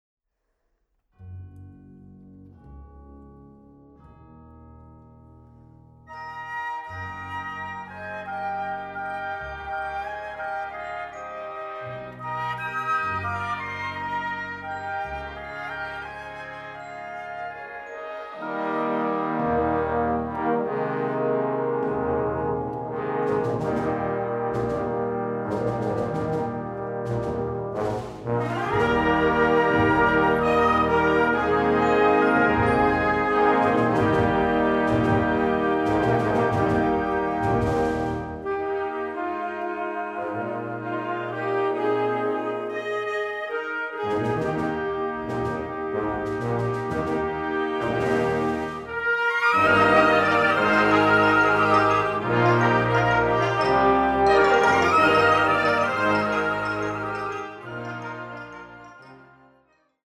Music for Symphonic Wind Orchestra